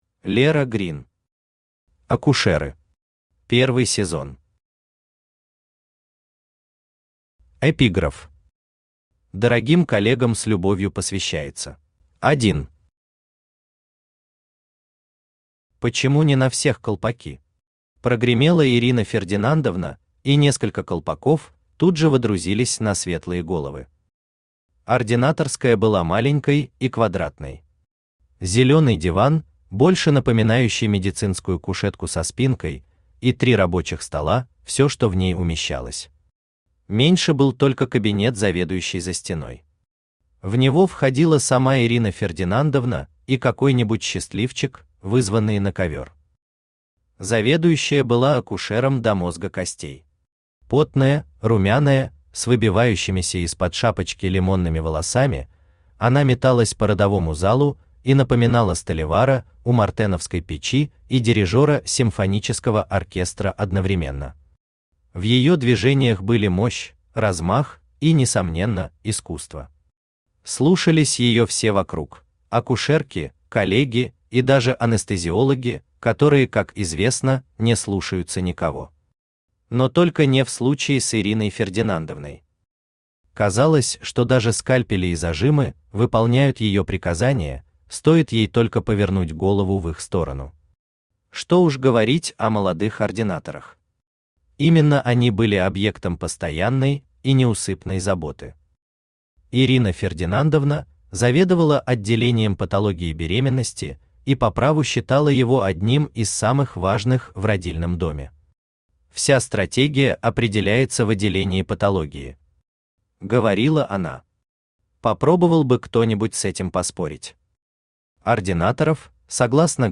Аудиокнига «Акушеры». Первый сезон | Библиотека аудиокниг
Первый сезон Автор Лера Грин Читает аудиокнигу Авточтец ЛитРес.